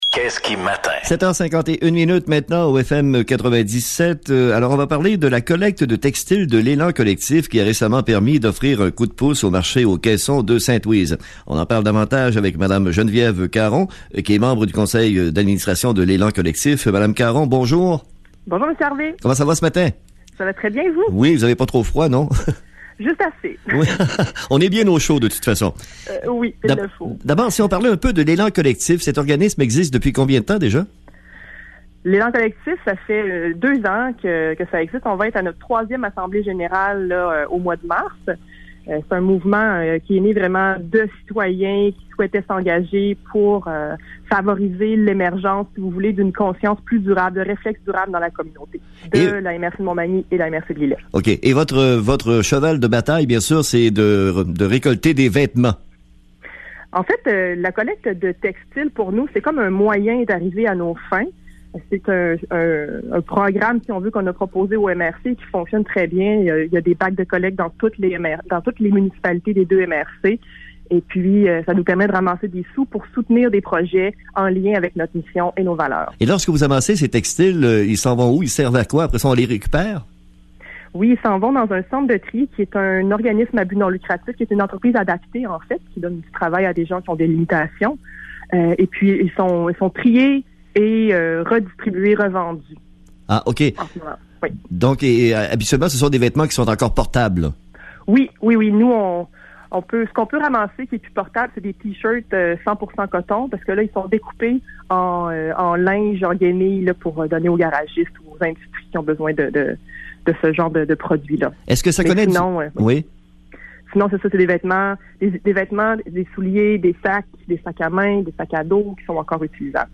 l’entrevue radiophonique